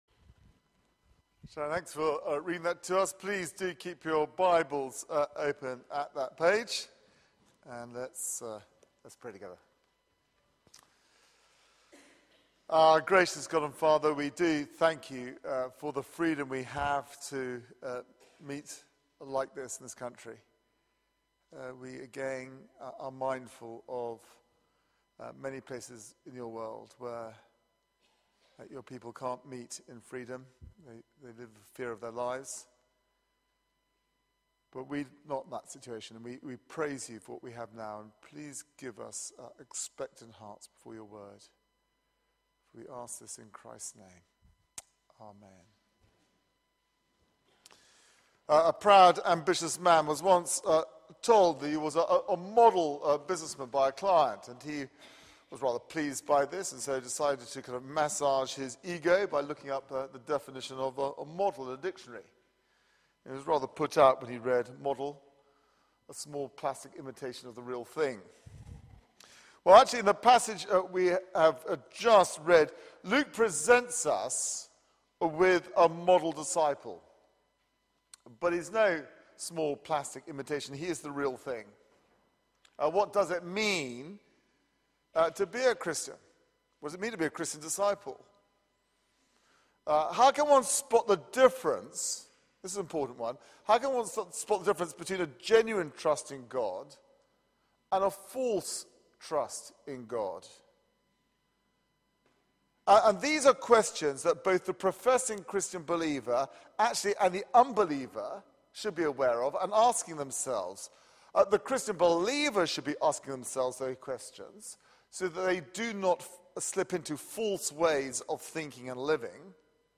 Media for 4pm Service on Sun 10th Nov 2013 16:00 Speaker
Sermon